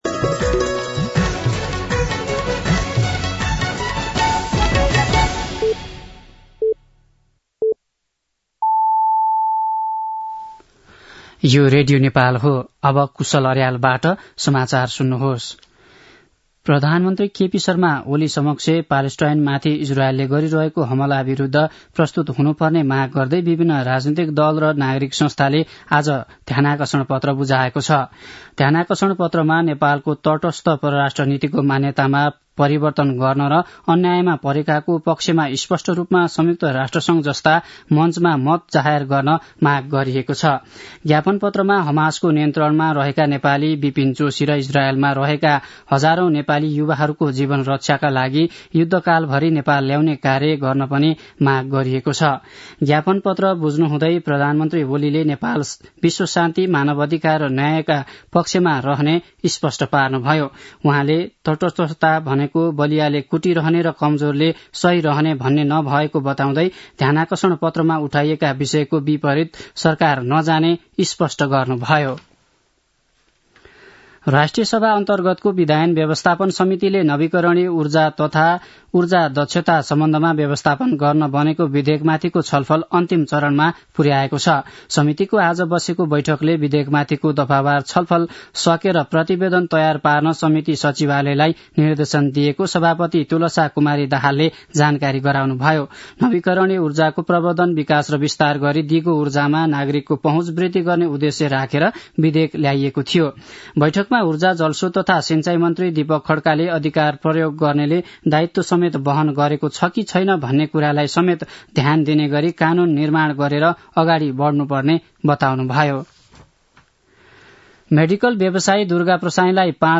दिउँसो ४ बजेको नेपाली समाचार : ६ मंसिर , २०८१
4-pm-nepali-news-1-2.mp3